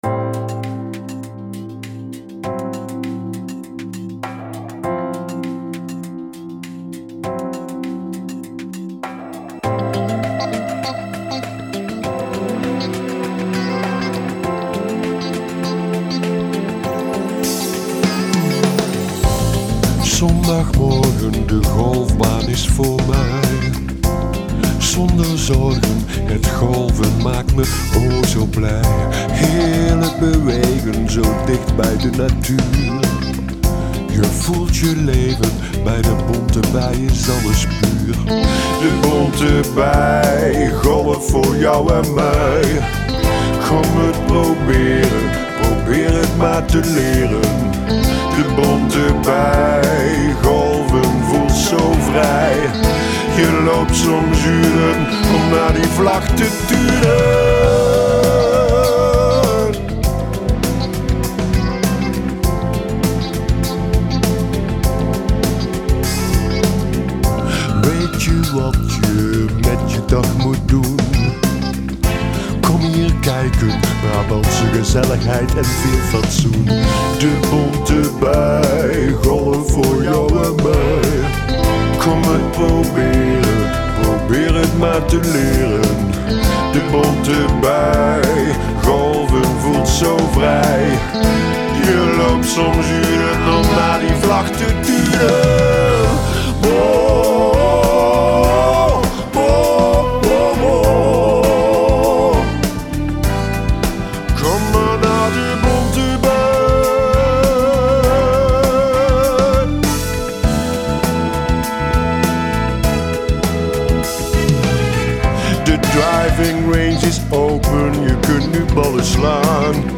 Wat een prachtig clublied!